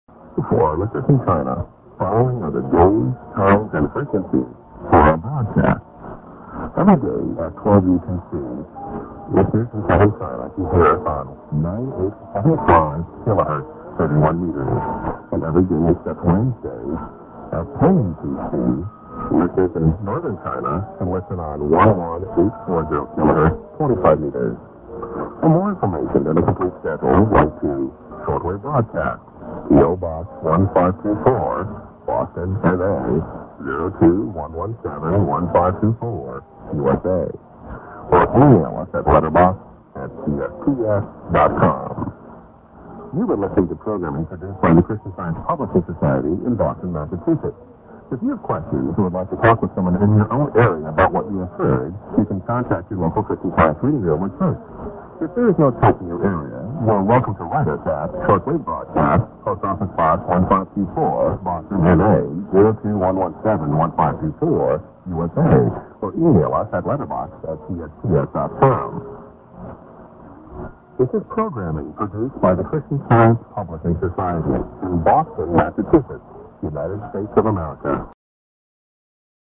End porg->SKJ->ADDR(post->Web->ID)(man)-> s/off | Via Taipei